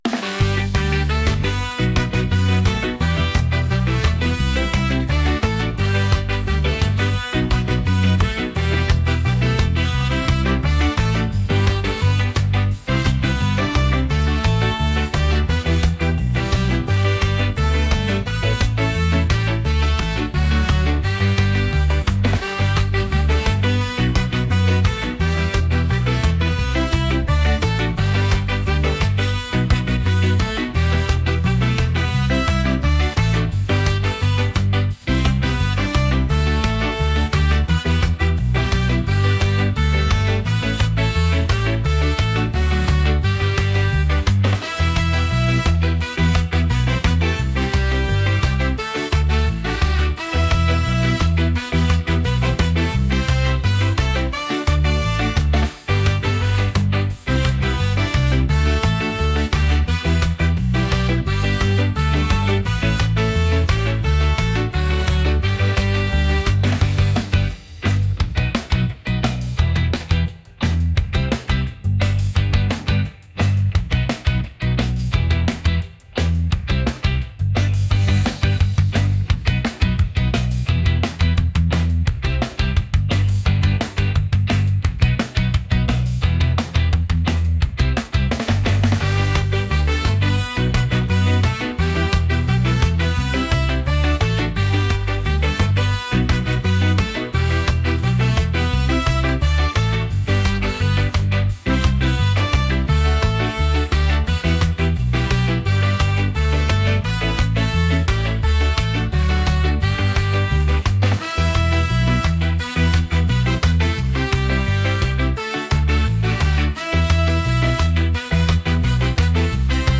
energetic | upbeat | ska